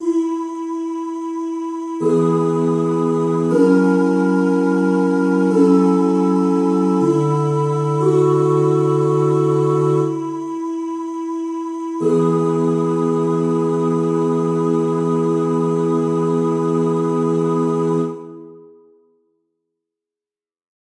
Key written in: E Major
How many parts: 4
Type: Barbershop
Take this nice and slow.
All Parts mix: